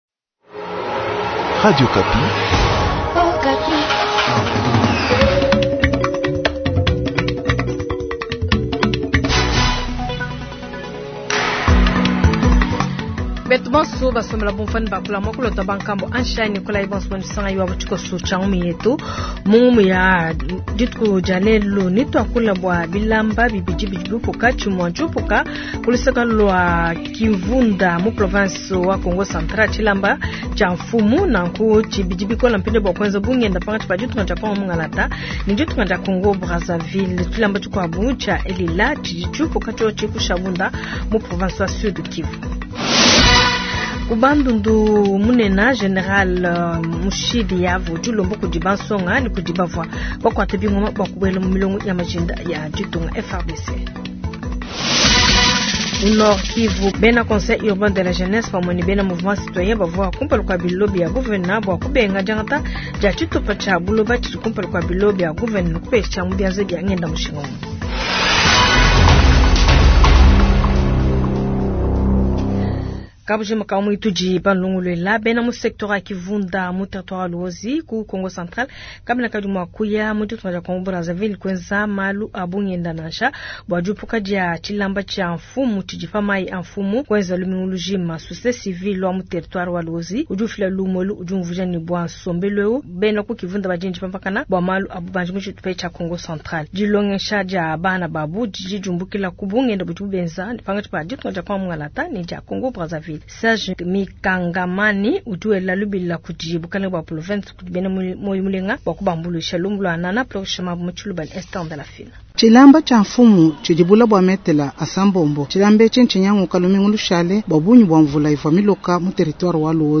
Jounal soir
Journal du Lundi 090123